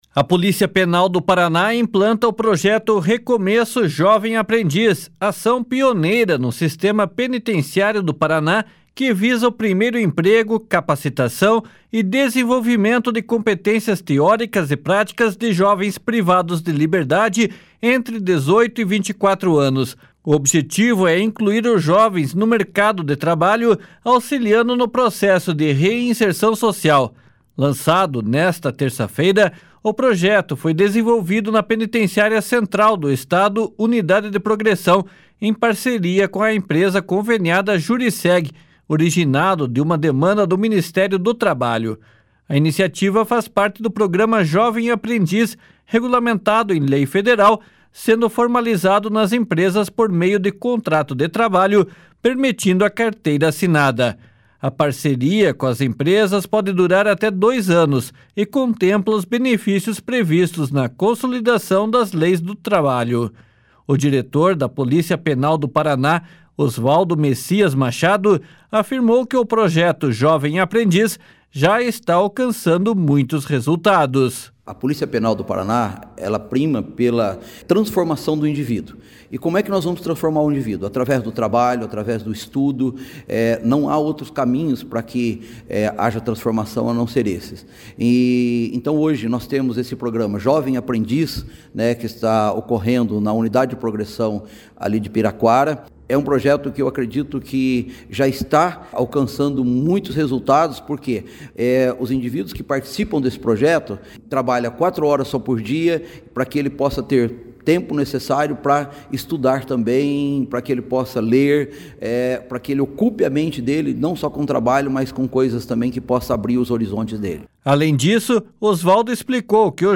O diretor-geral da Polícia Penal do Paraná, Osvaldo Messias Machado, afirmou que o Projeto Jovem Aprendiz já está alcançando muitos resultados.